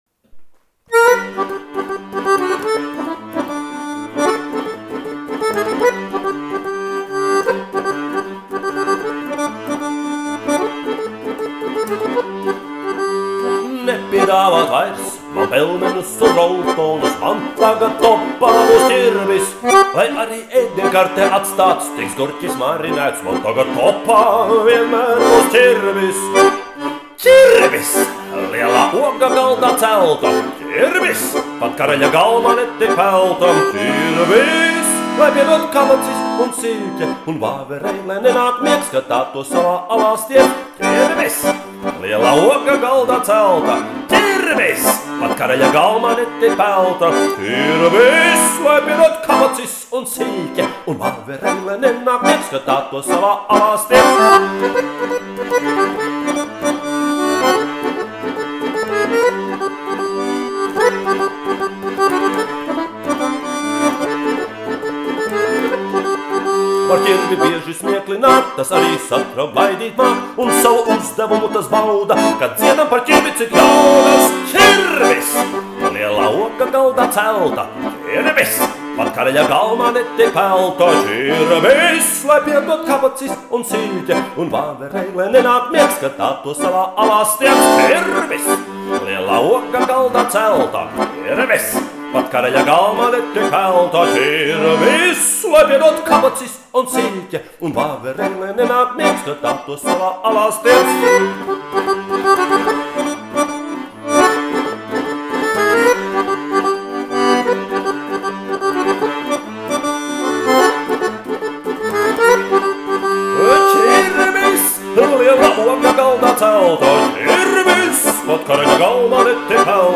Taču vislielākā balva ir RUDENS BALLES KOPDZIESMA – "Ķirbis" (klausīties skaļi), kurai tekstu radīja skolēni ar savām piedāvātajām frāzēm balles laikā.